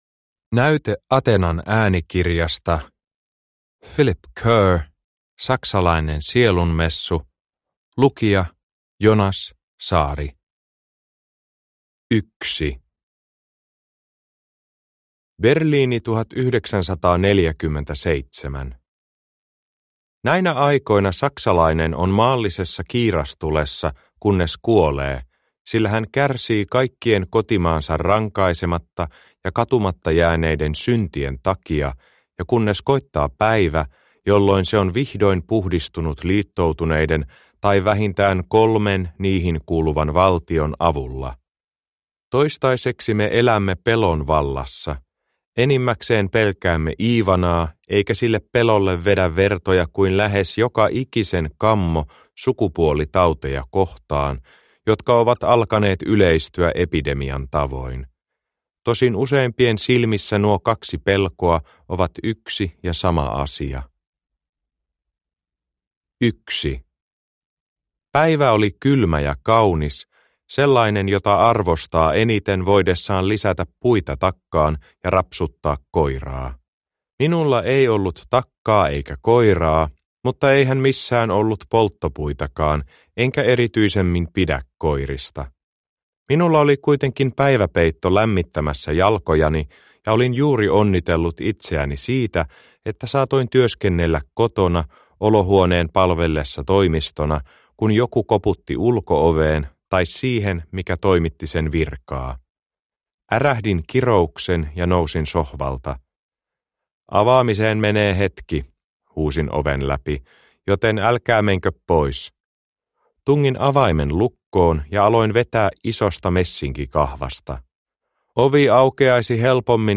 Saksalainen sielunmessu – Ljudbok – Laddas ner